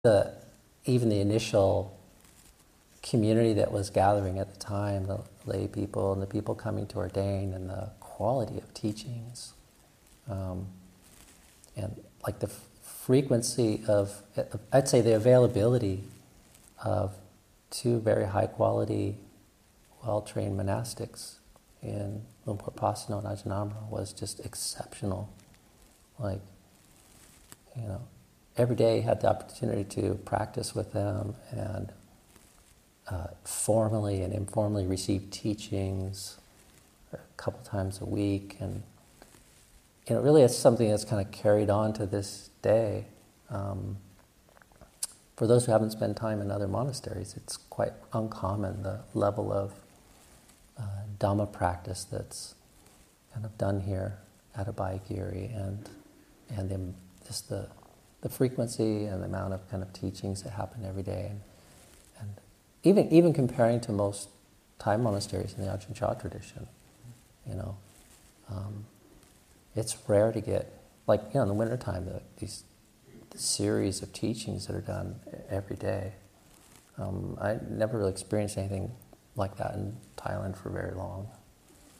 Abhayagiri 25th Anniversary Retreat [2021], Session 6, Excerpt 5